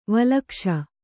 ♪ valakṣa